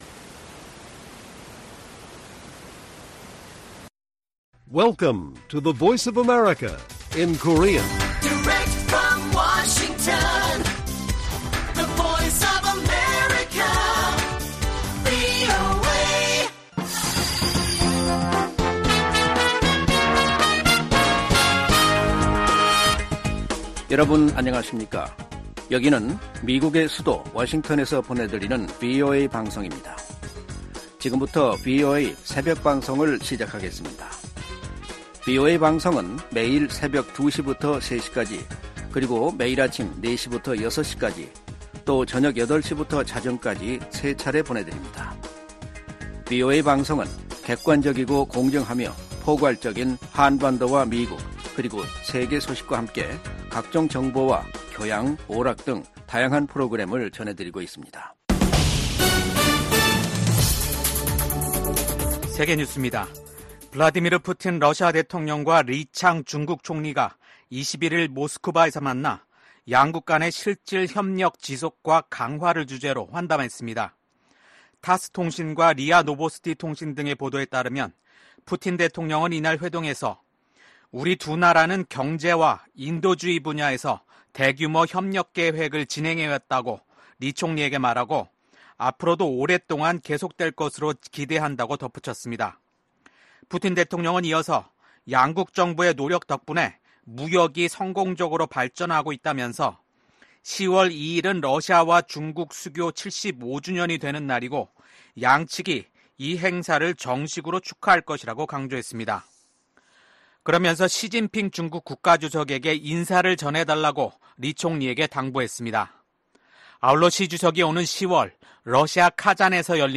VOA 한국어 '출발 뉴스 쇼', 2024년 8월 22일 방송입니다. 미국 국방부는 미한 연합훈련인 을지프리덤실드 연습이 방어적 성격이란 점을 분명히 하며 ‘침략 전쟁 연습’이란 북한의 주장을 일축했습니다.